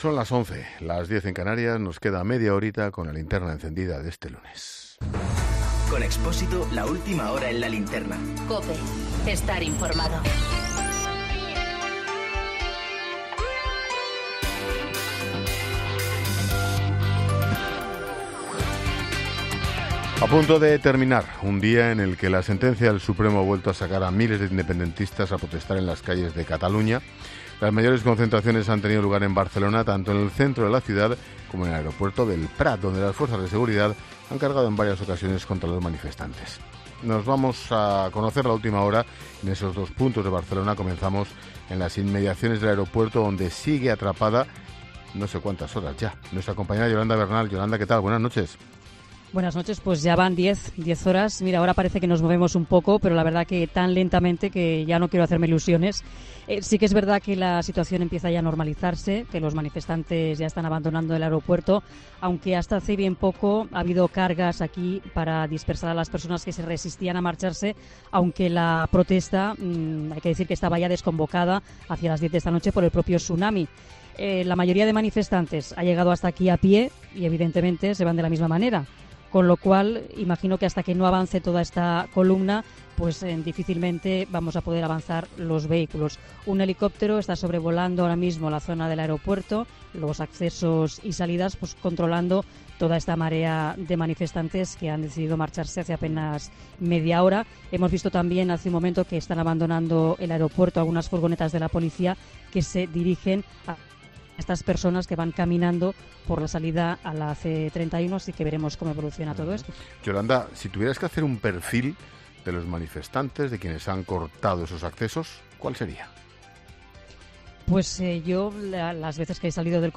Boletín de noticias COPE del 14 de octubre de 2019 a las 23.00 horas